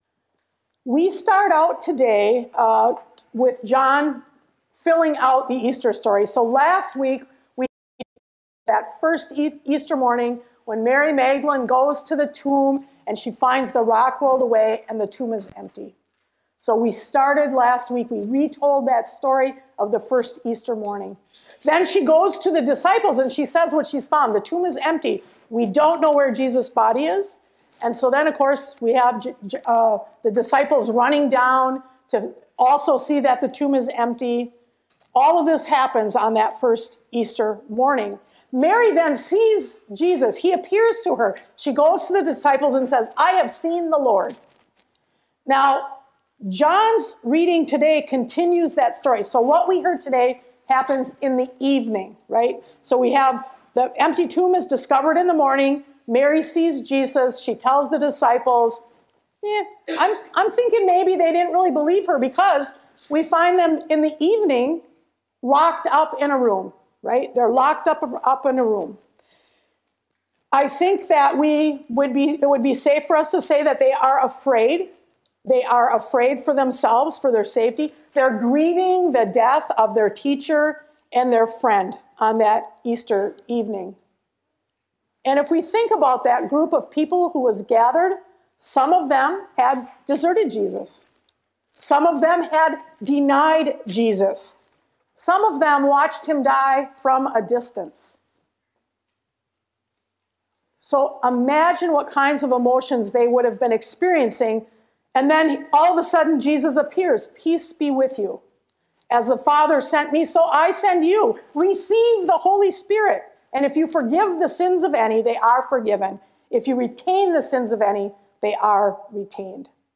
Sermons | Eleva Lutheran Church